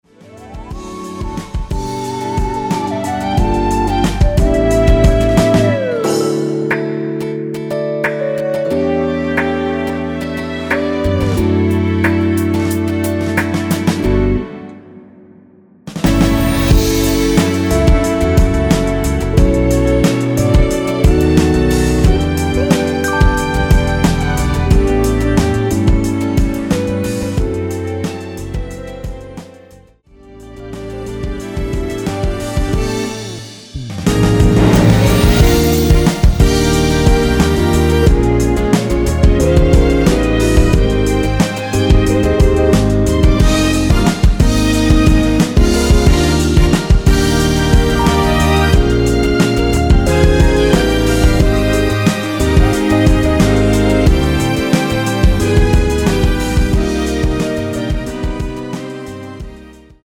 원키에서(-1)내린 MR입니다.
D
앞부분30초, 뒷부분30초씩 편집해서 올려 드리고 있습니다.